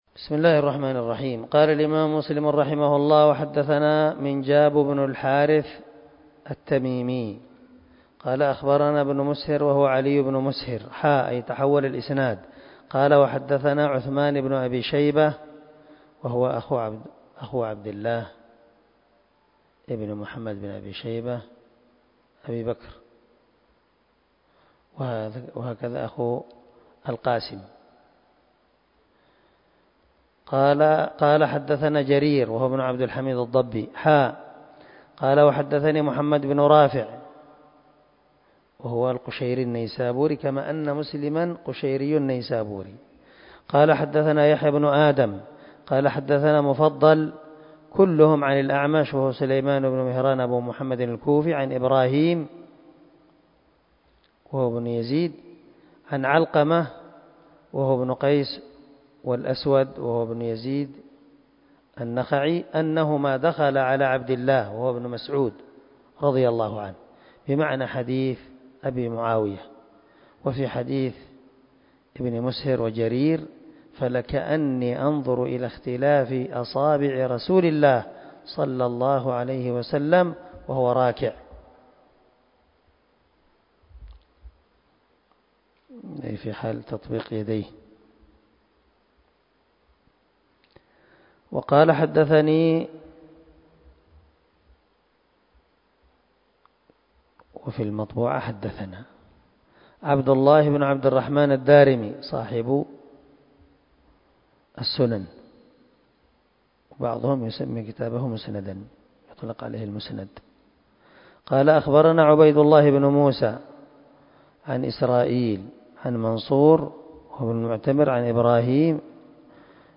336الدرس 8 من شرح كتاب المساجد ومواضع الصلاة حديث رقم ( 535 ) من صحيح مسلم